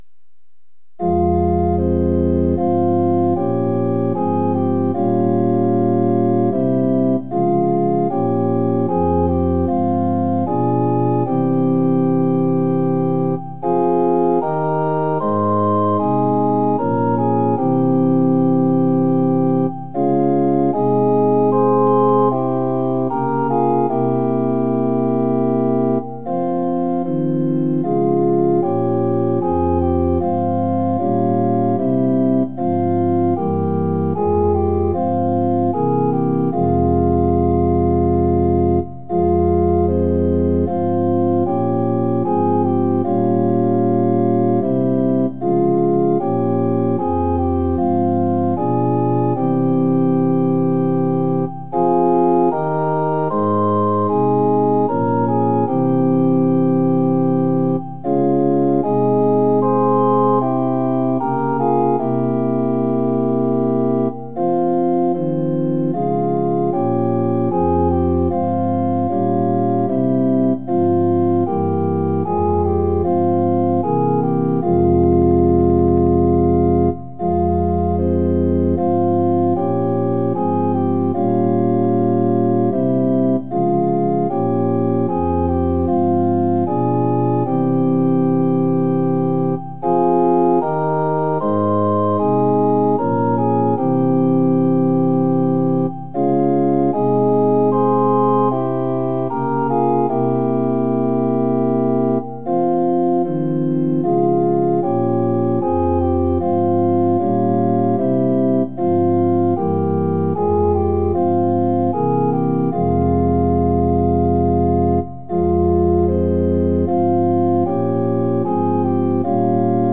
◆　４分の４拍子：　４拍目から始まります。